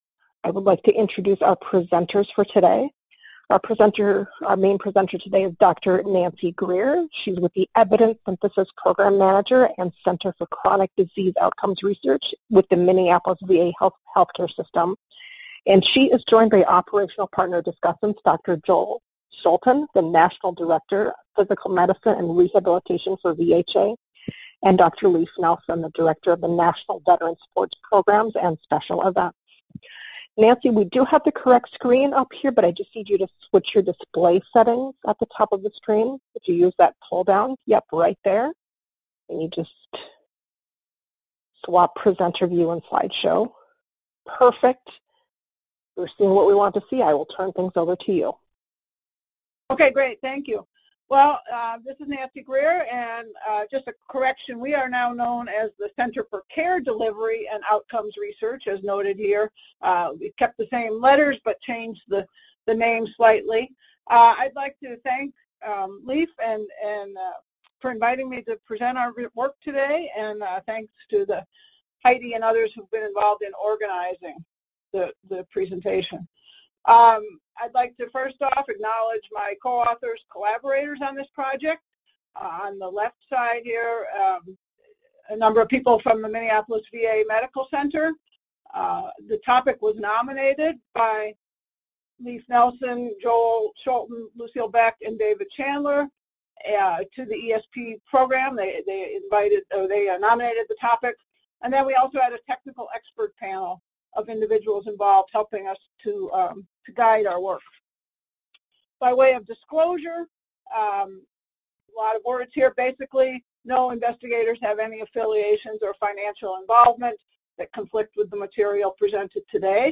Description: The purpose of this presentation is share findings from a systematic review of the evidence on 1) benefits and harms of adaptive sports participation and 2) barriers to and facilitators of participation, conducted by the Minneapolis VA Evidence Synthesis Program site.